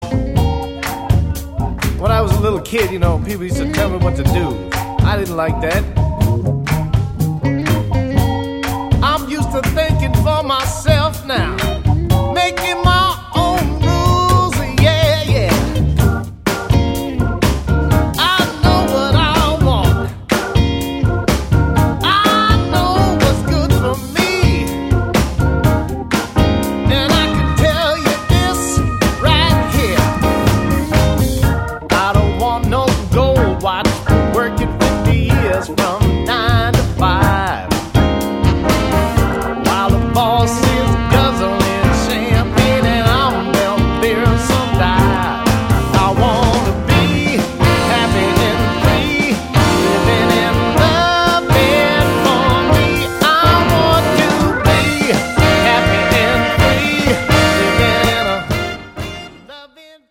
Swinging Jazz, Rocking R&B and Funky Blues all come together